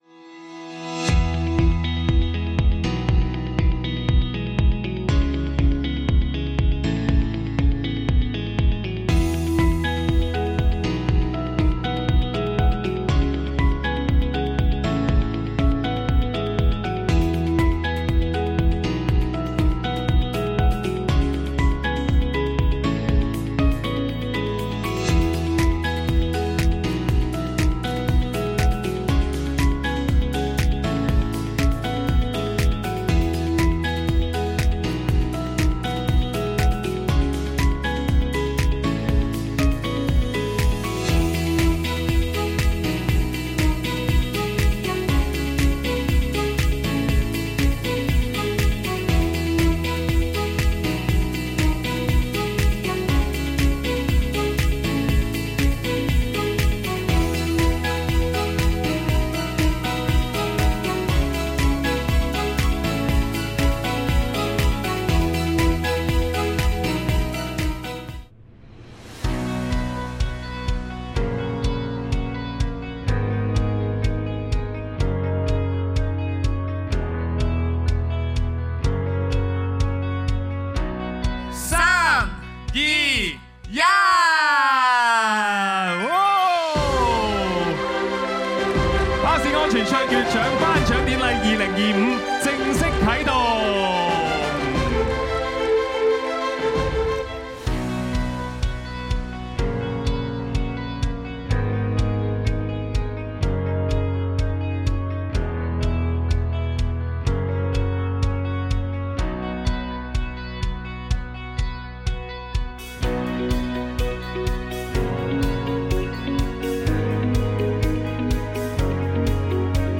Highlights of the Bus Safety Excellence Award Ceremony 2025
Audio of video_Highlights of Ceremony 2025.mp3